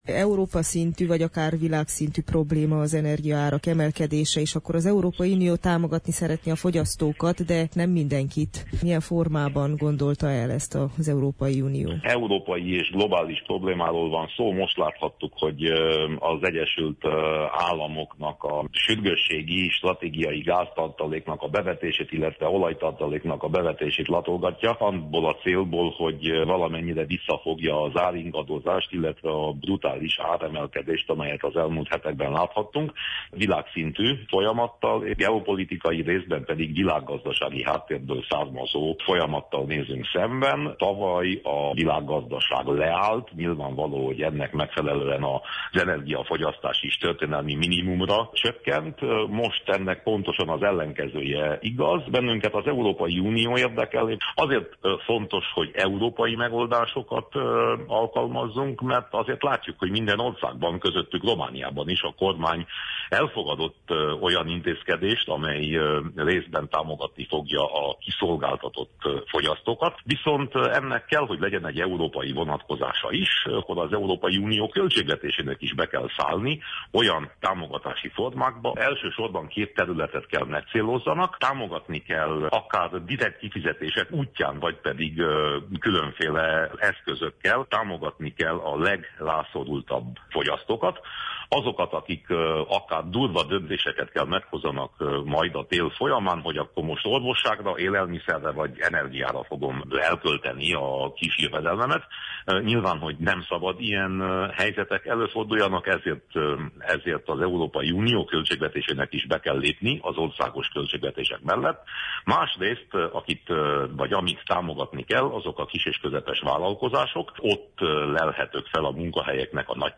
Winkler Gyula EP képviselővel beszélgettünk.